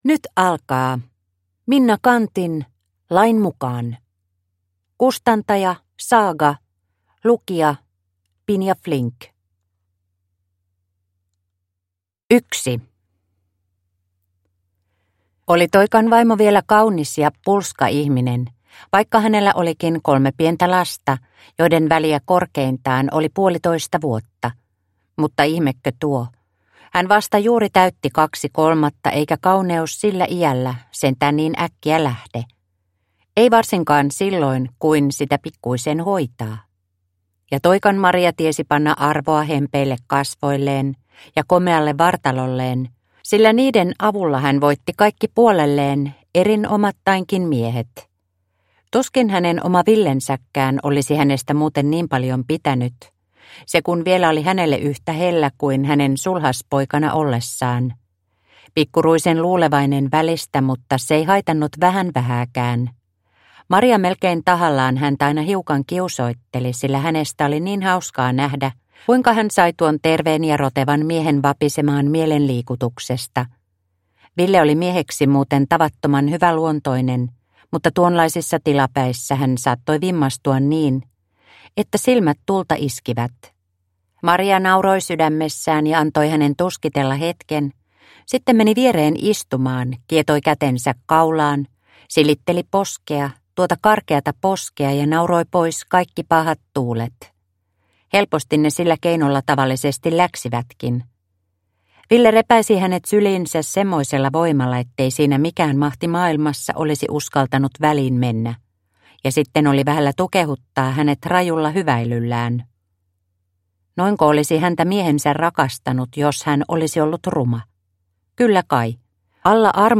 Lain mukaan – Ljudbok